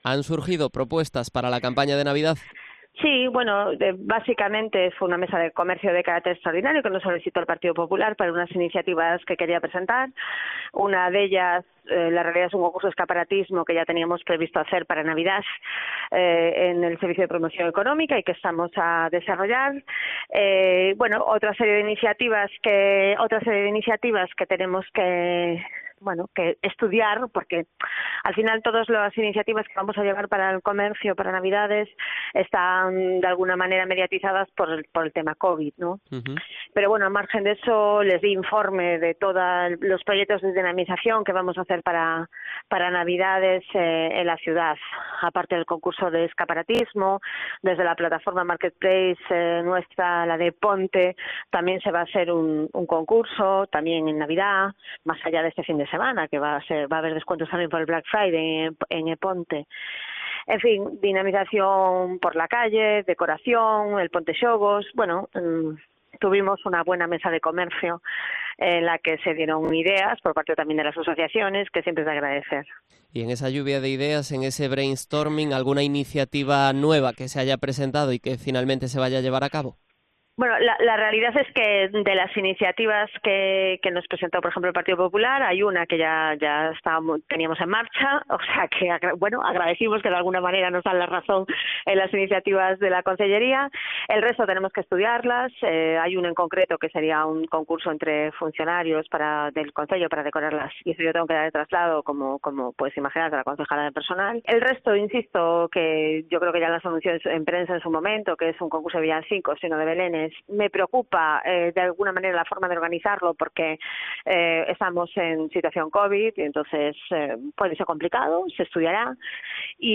Entrevista a Yoya Blanco, edil de Promoción Económica en Pontevedra